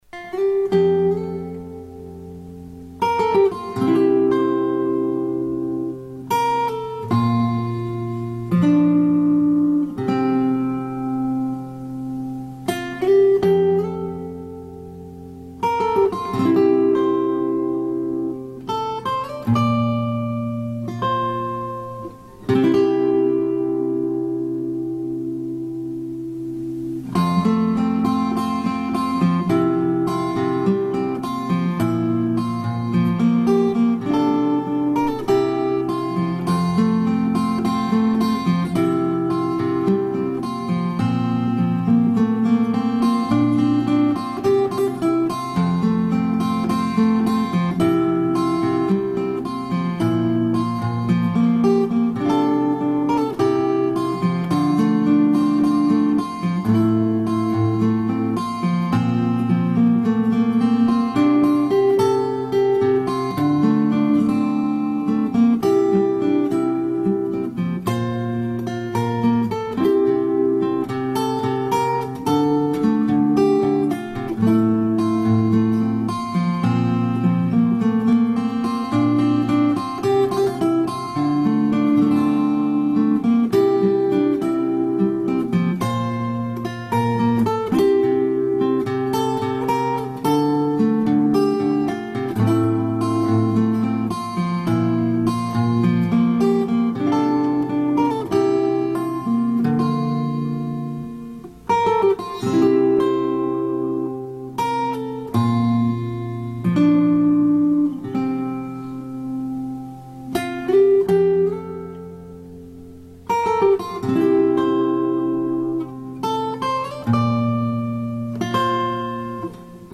соло на гитаре